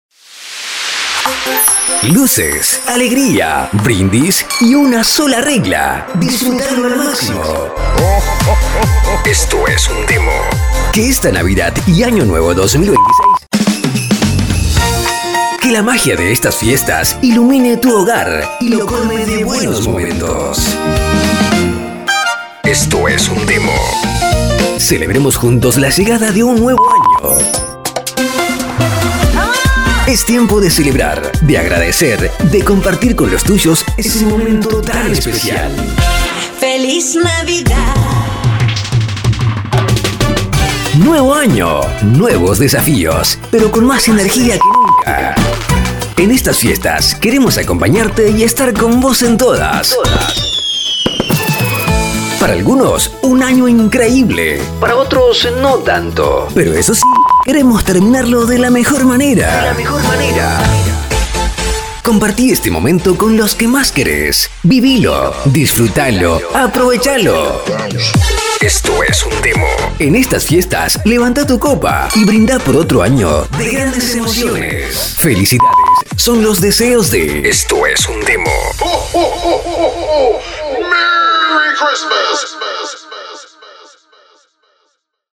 Una artística para festejar la mejor época del año, con ritmos dinámicos y modernos.
Una voz con personalidad que hace la diferencia
DEMO_ARTE_NAVIDAD_HAPPY.mp3